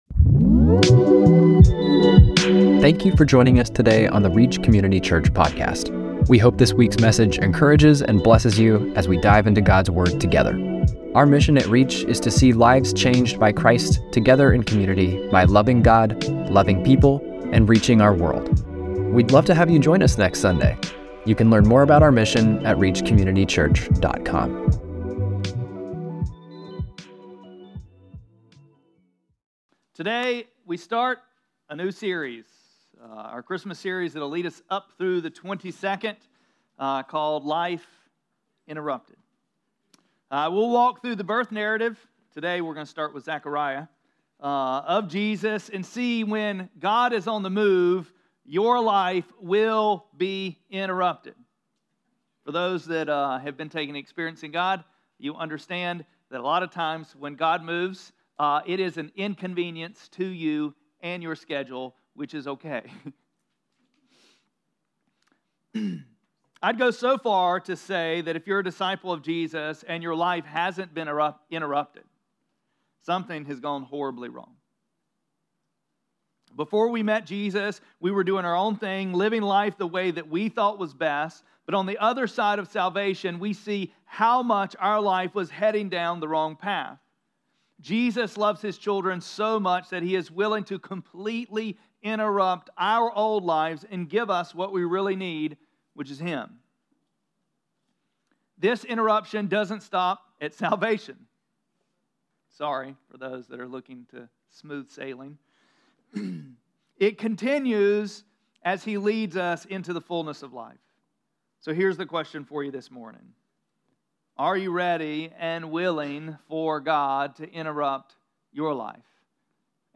12-1-24-Sermon.mp3